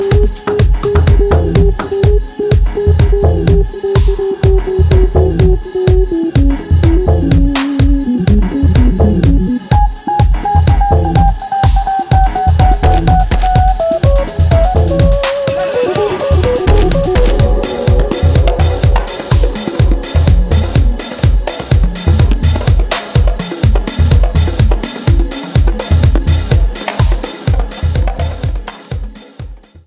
REMIX TRACKS